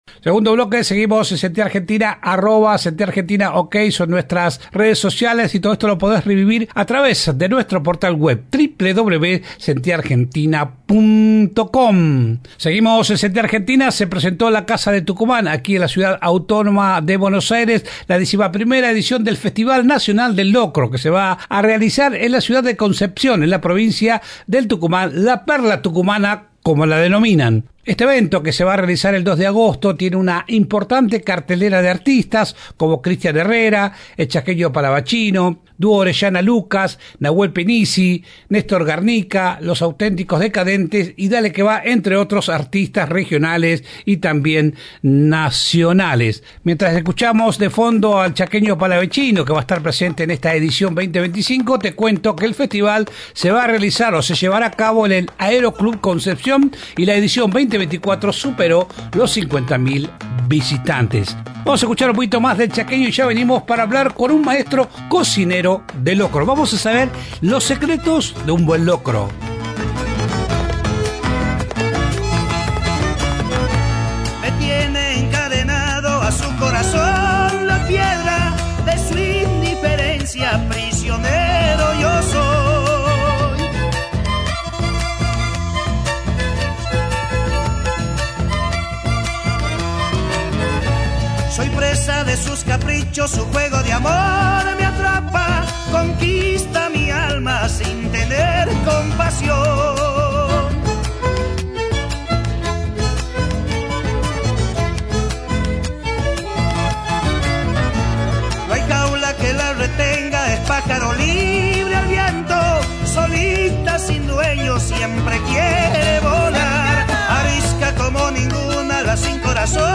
El intendente de Concepción Alejandro Molinuevo en Sentí Argentina por AM 590 Radio Continental.